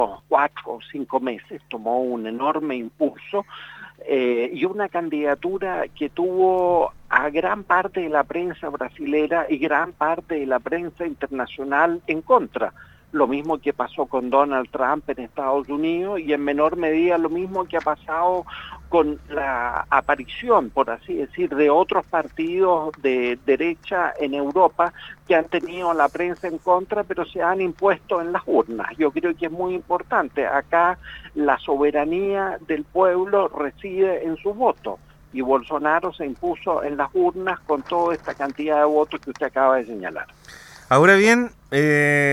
En Radio Sago: Diplomático analiza llegada de Bolsonaro al poder - RadioSago